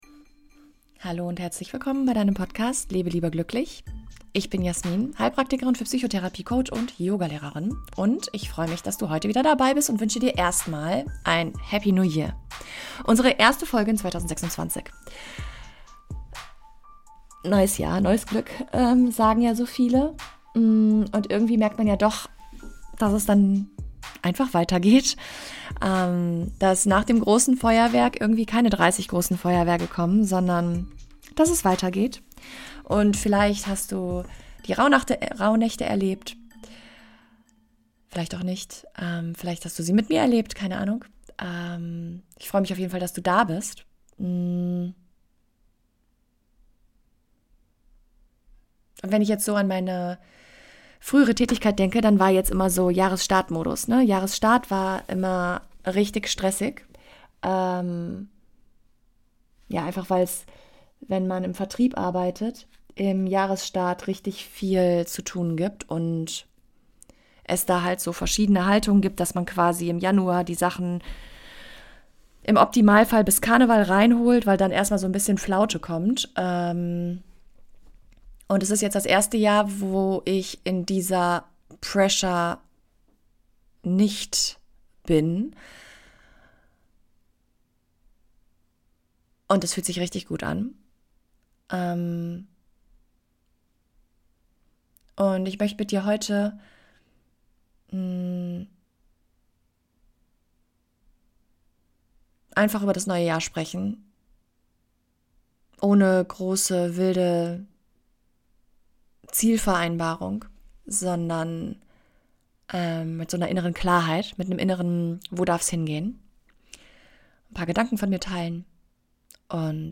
Eine ruhige und herzliche Folge für alle, die das neue Jahr nicht optimieren, sondern wahrhaftig beginnen möchten.